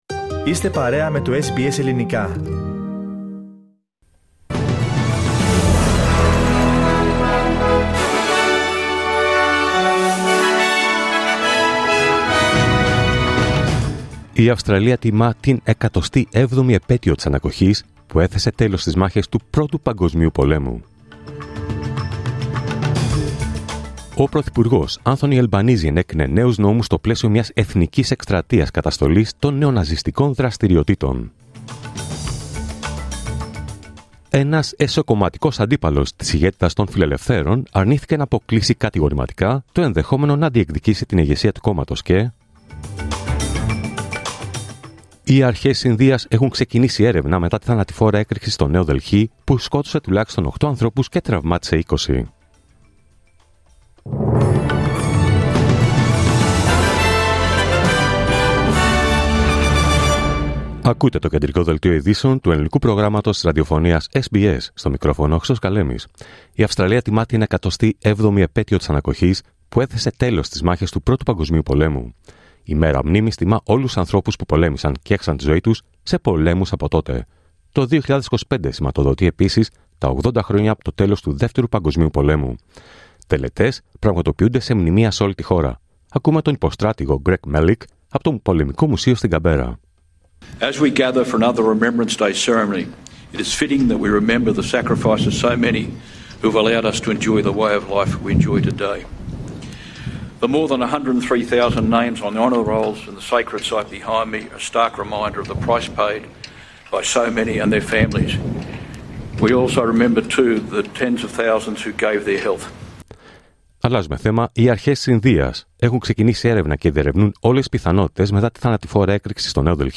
Δελτίο Ειδήσεων Τρίτη 11 Νοεμβρίου 2025